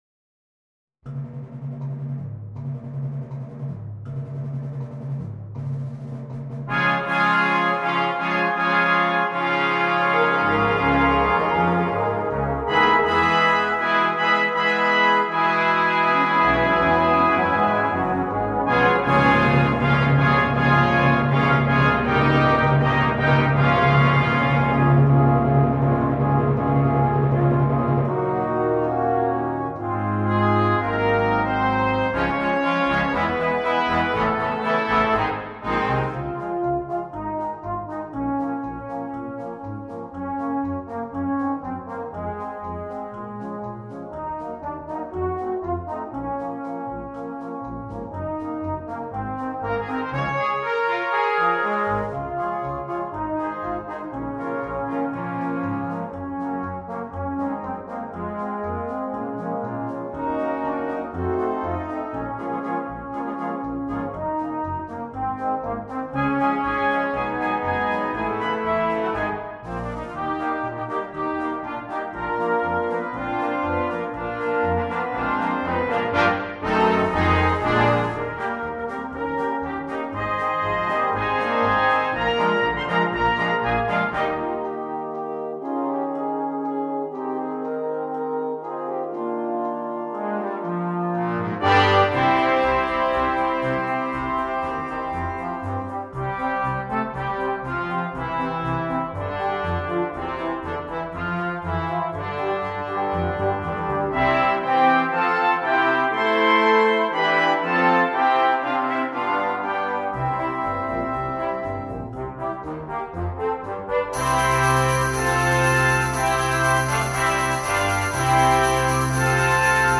2. Brass Band
sans instrument solo
Musique de Noël
Medley